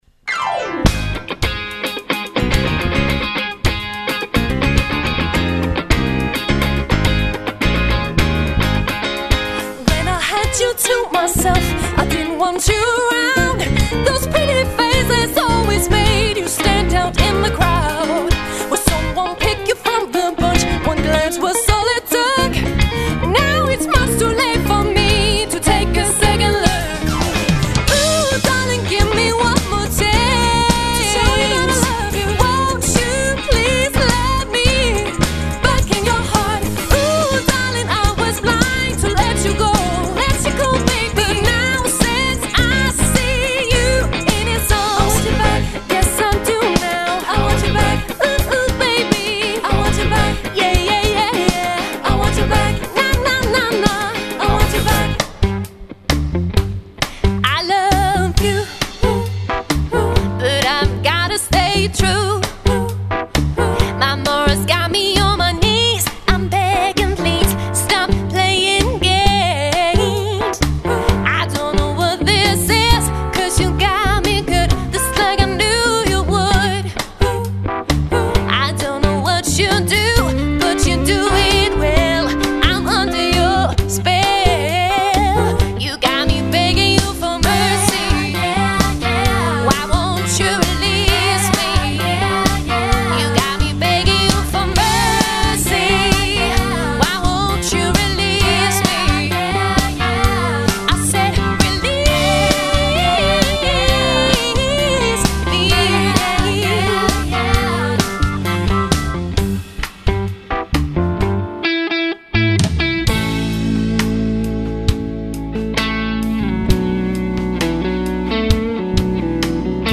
danseorkester
• Allround Partyband
• Coverband